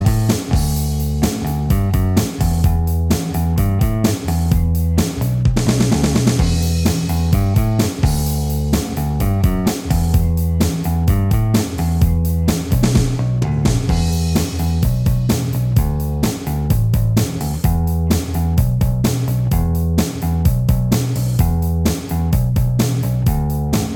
Minus Guitars Indie / Alternative 3:14 Buy £1.50